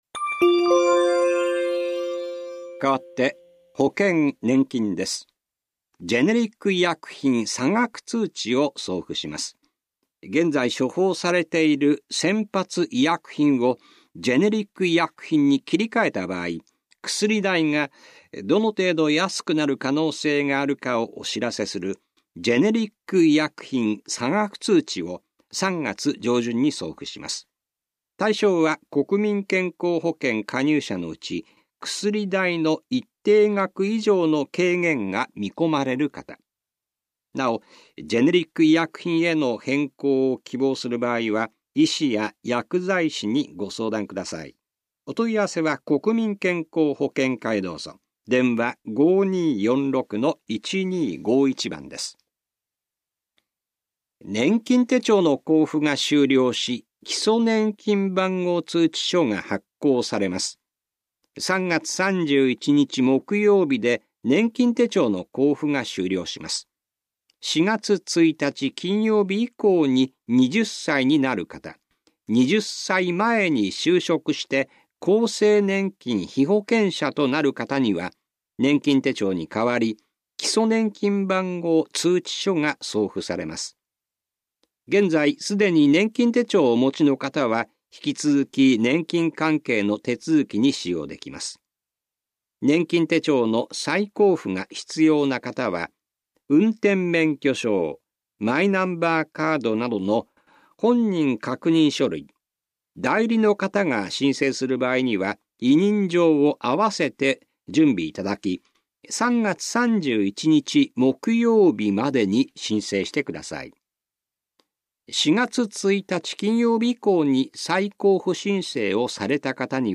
広報「たいとう」令和4年3月5日号の音声読み上げデータです。